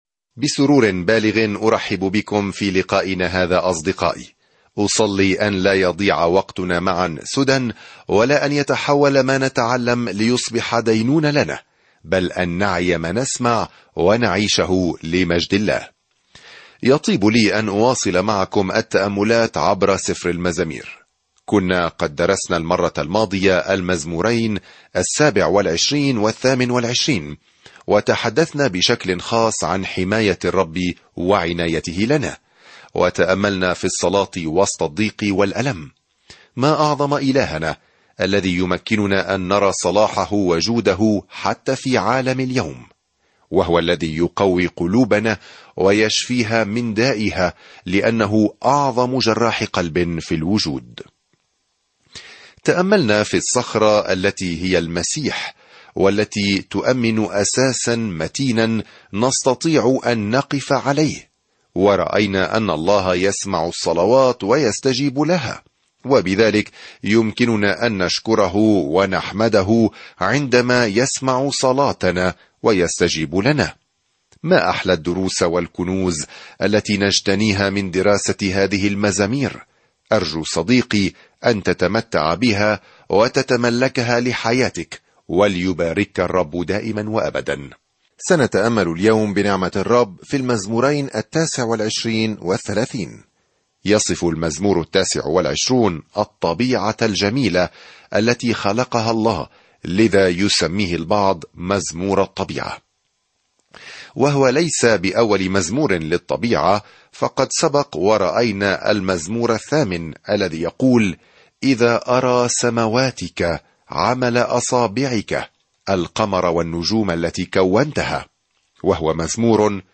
الكلمة اَلْمَزَامِيرُ 29 اَلْمَزَامِيرُ 30 يوم 17 ابدأ هذه الخطة يوم 19 عن هذه الخطة تعطينا المزامير أفكارًا ومشاعر مجموعة من التجارب مع الله؛ من المحتمل أن كل واحدة تم ضبطها في الأصل على الموسيقى. سافر يوميًا عبر المزامير وأنت تستمع إلى الدراسة الصوتية وتقرأ آيات مختارة من كلمة الله.